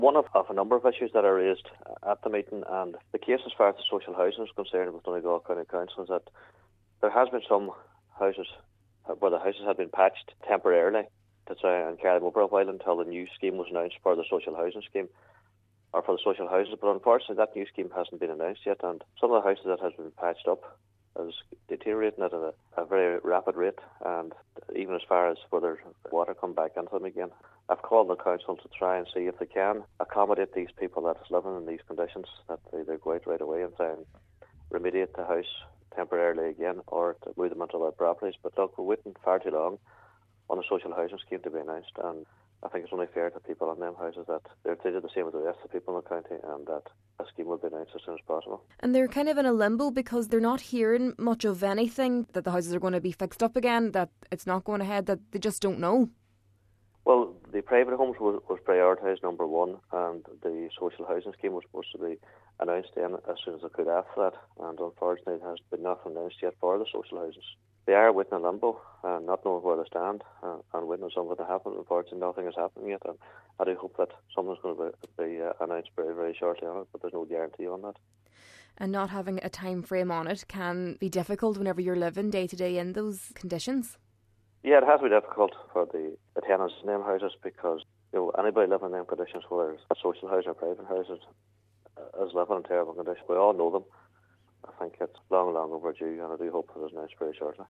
He requested an update at the latest meeting of the council’s Defective Block Committee…….